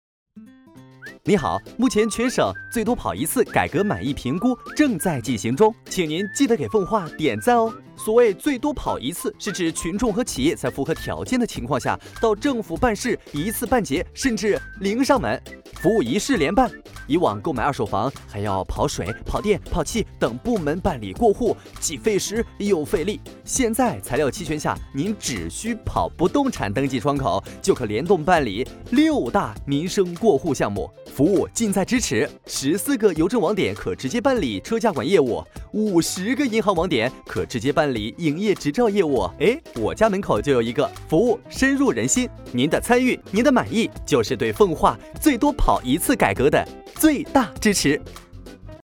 男国350年轻时尚配音-新声库配音网
男国350_宣传片_病毒_政党奉化.mp3